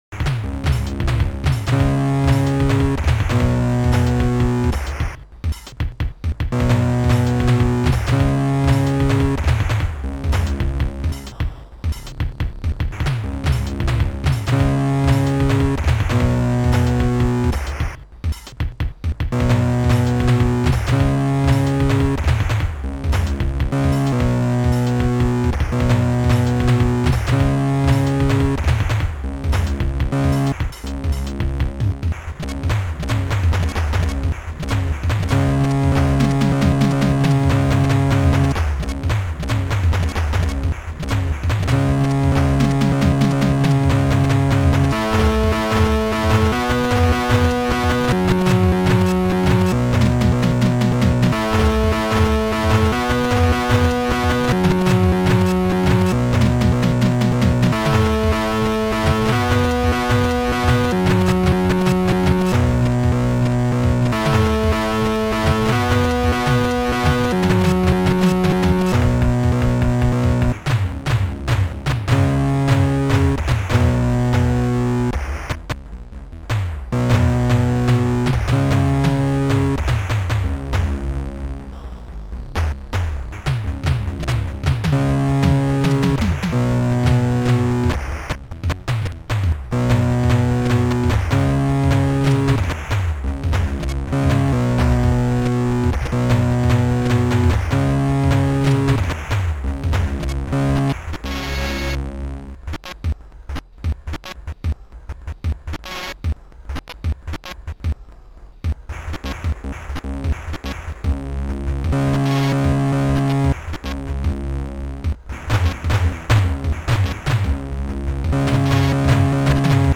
Protracker Module
ST-09:hihatopen2 ST-10:tbchord2 ST-10:tbchord5 ST-10:tbchord6 ST-10:janet1 ST-10:snare2 ST-06:rtbassdrum ST-01:polysynth